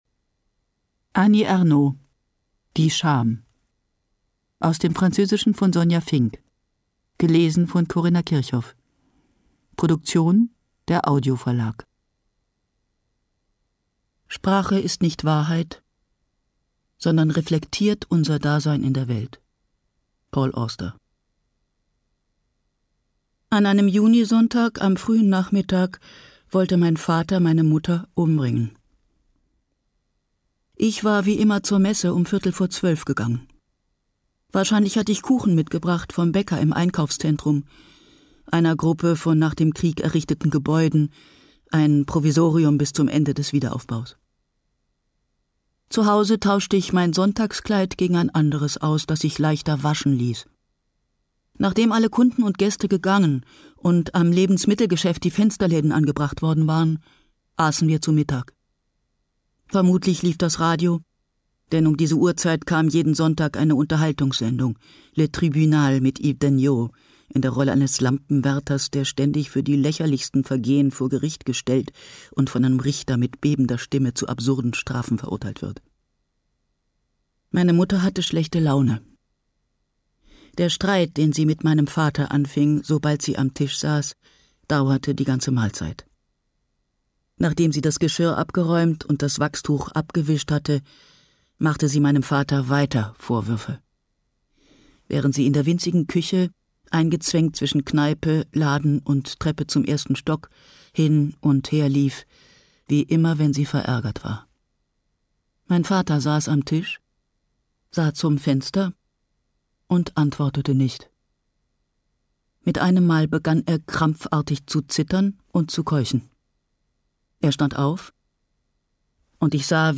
Ungekürzte Lesung mit Corinna Kirchhoff (2 CDs)
Corinna Kirchhoff (Sprecher)